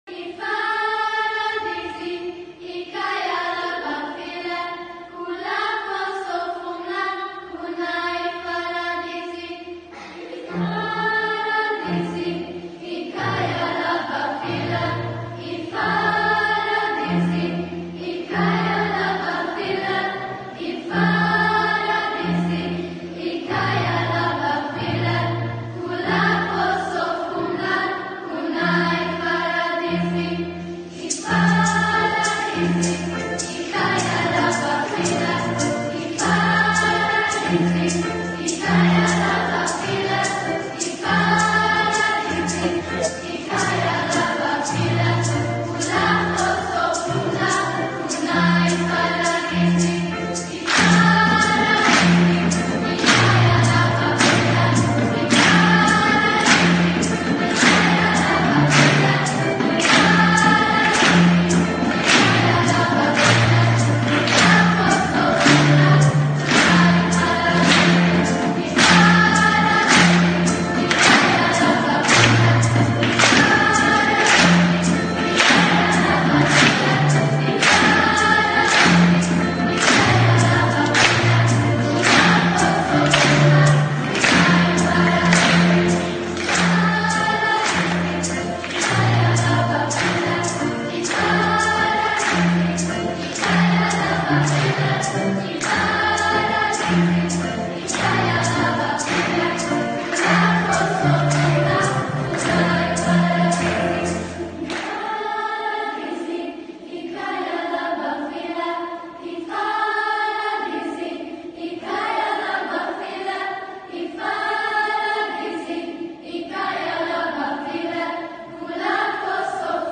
Immáron második alkalommal került megrendezésre a Tavaszi zsongás elnevezésű hangversenyünk.